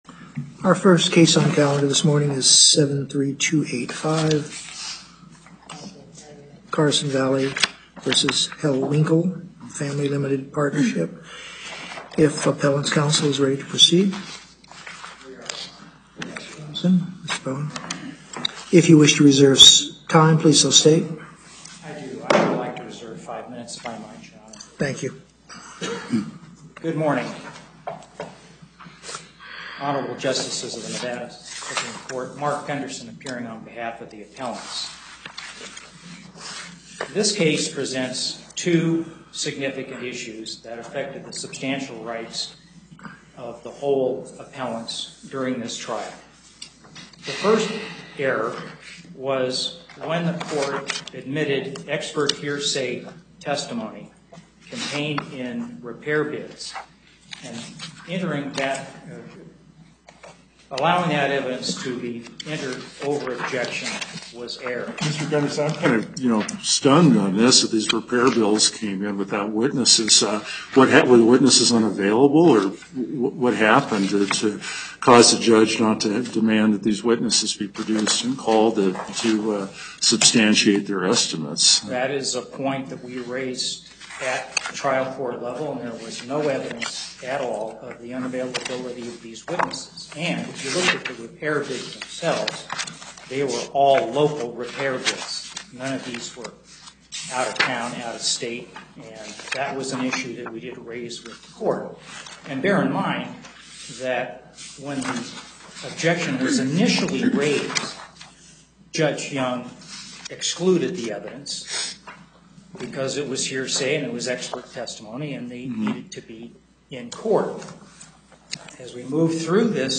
Location: Carson City Before the En Banc Court, Chief Justice Douglas presiding